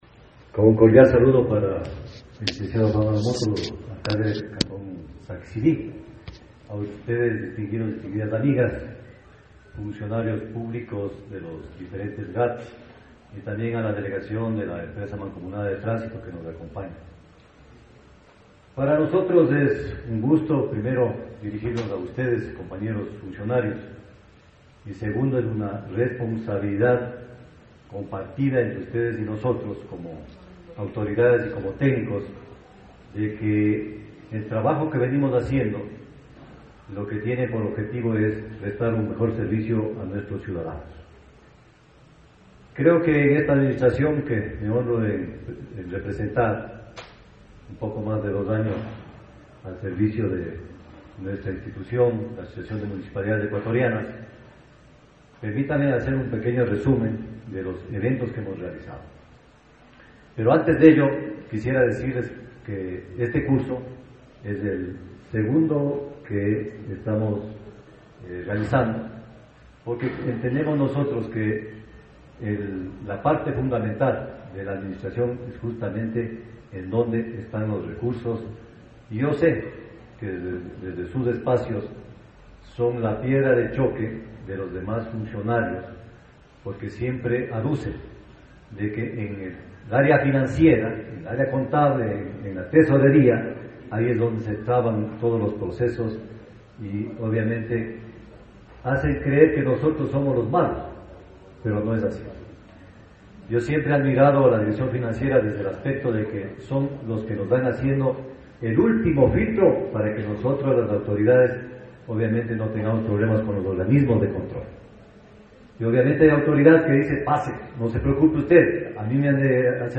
Con la participación de sesenta delegados de municipios de las provincias de Chimborazo, Cotopaxi, Tungurahua y Pastaza, el mismo que está bajo la coordinación técnica de la regional 3 de Riobamba de la Asociación de Municipalidades del Ecuador y se desarrolla del 17 al 19 de julio en el salón máximo del GADMICS.
Intervención del Dr. Mario Andino – Presidente de AME